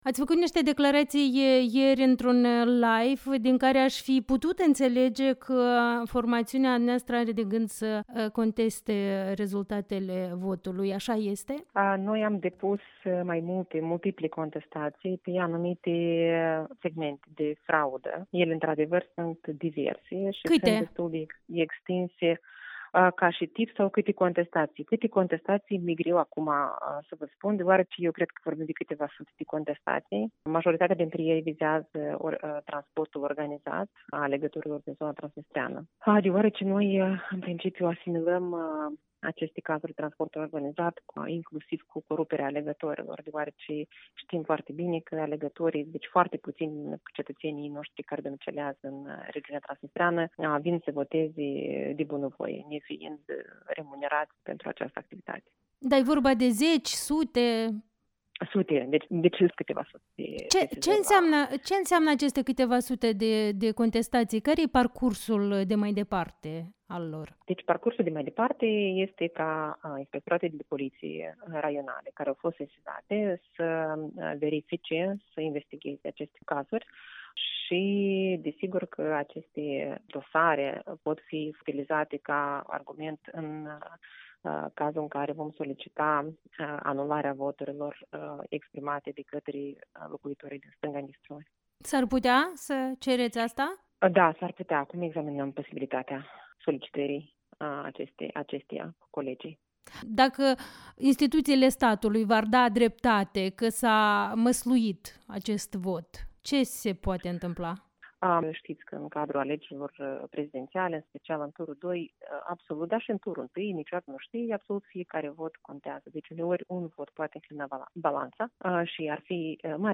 Interviu matinal cu Olesea Stamate, fost ministru al Justiției